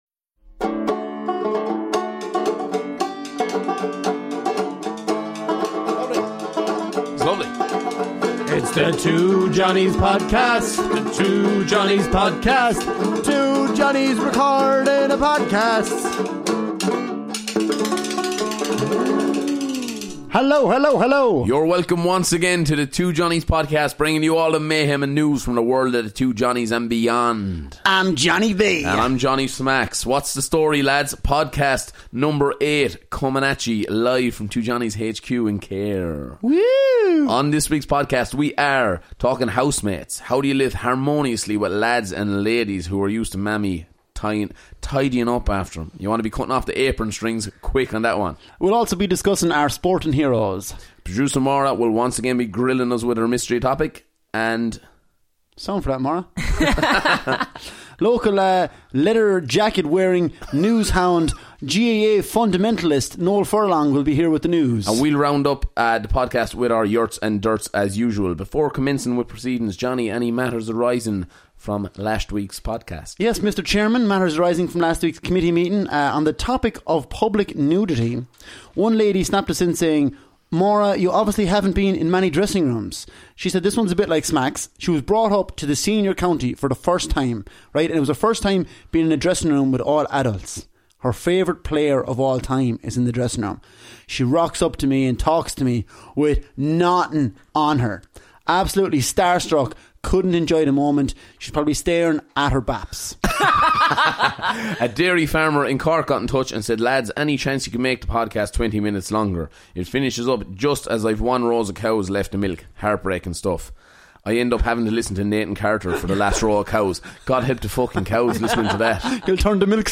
Ireland's favourite comedy duo